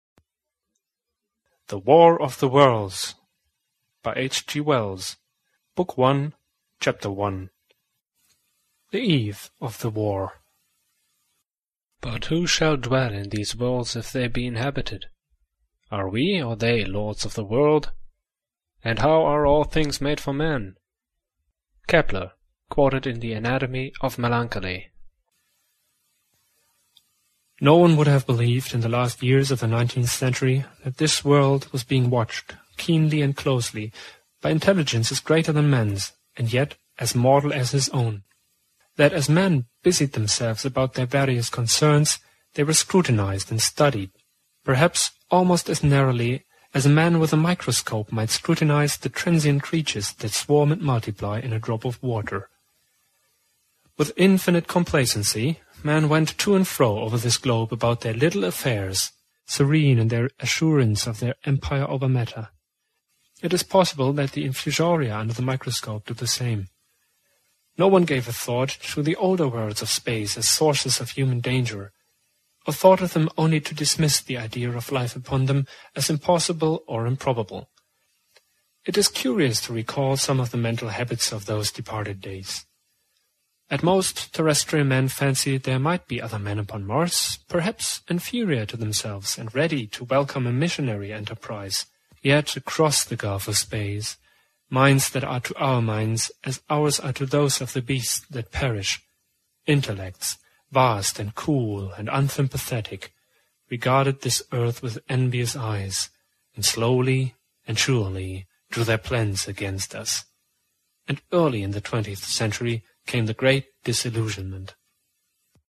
The War of the Worlds MP3 CD Audiobook in DVD case. Read by multiple readers. 6 hours and 40 minutes on one disc.